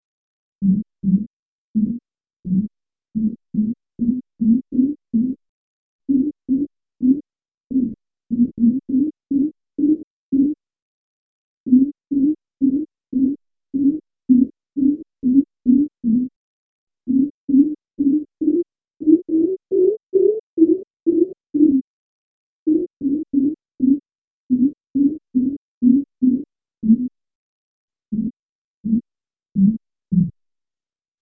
I've set the relative frequency range to roughly what he indicates and inserted a pause between lines.